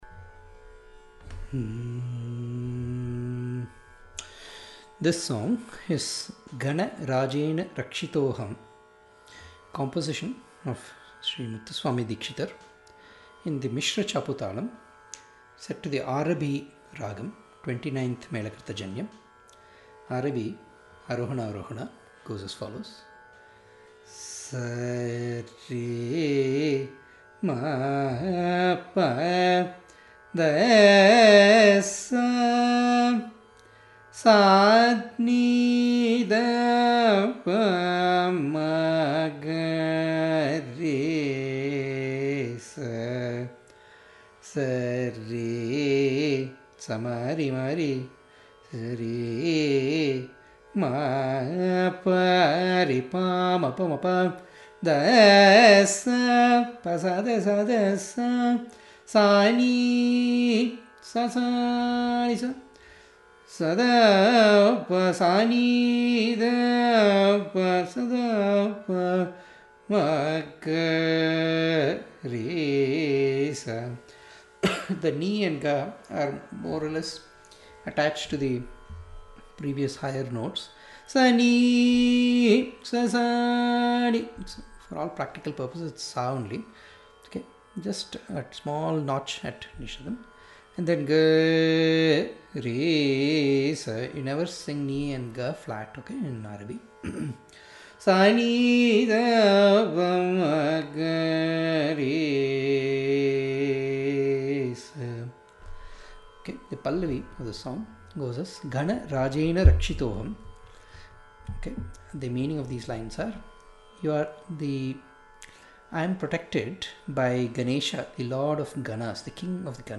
gaNa rAjEna rakshitOhaM (Class / Lesson) - Arabhi - Misra Chapu - Dikshitar
Ragam: Aarabhi (29th Mela Janyam)
ARO: S R2 M1 P D2 S ||
AVA: S N3 D2 P M1 G3 R2 S ||
Talam: Mishra Chapu